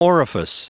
Prononciation du mot orifice en anglais (fichier audio)
orifice.wav